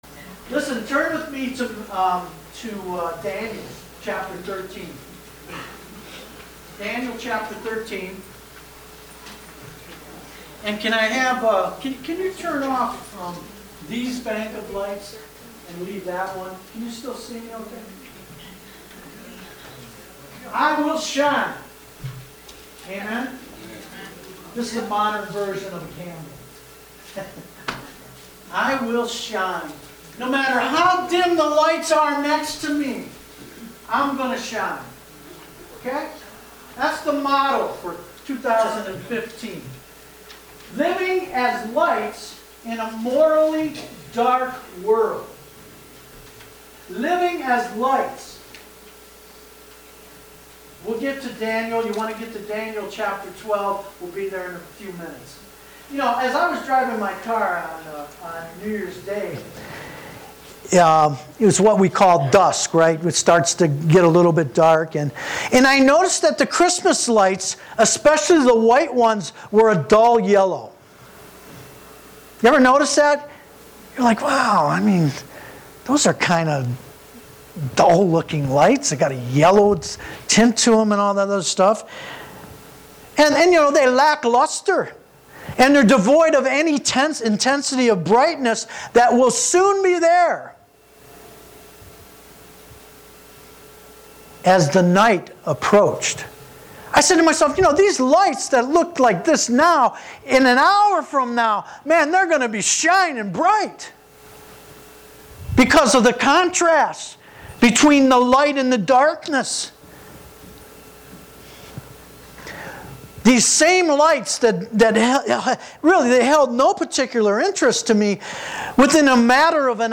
September 8, 2013 (Sunday Morning Service)